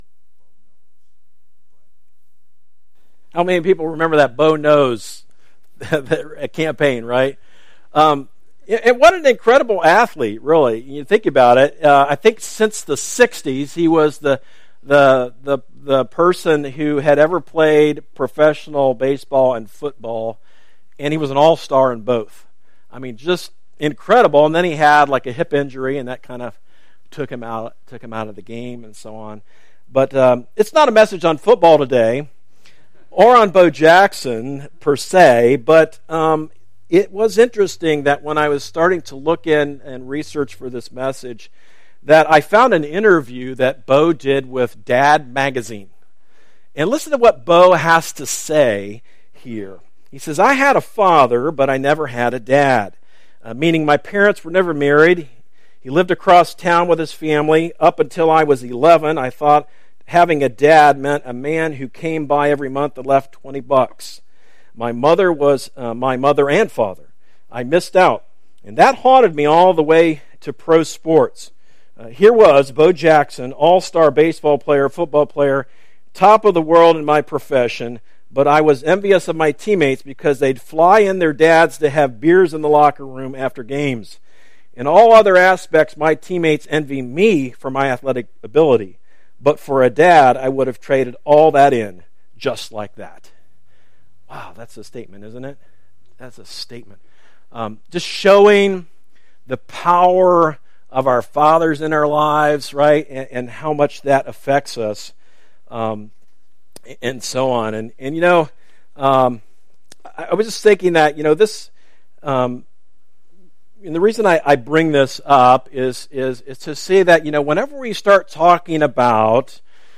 A message from the series "He Will Be Called . . .."